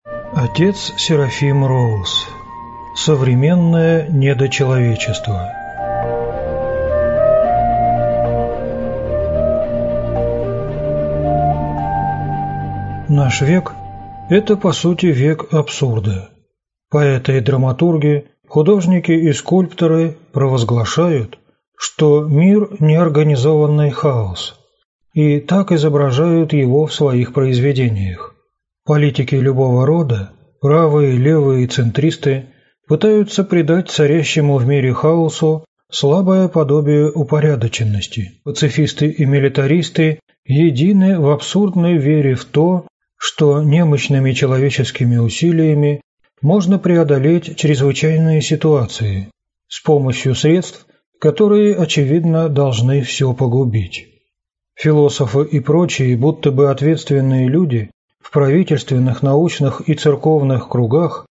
Аудиокнига Современное недочеловечество | Библиотека аудиокниг